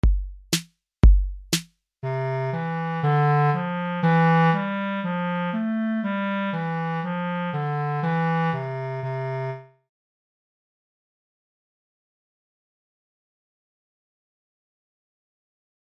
Deseguido atoparedes as diferentes melodías para imitar empregando os vosos instrumentos.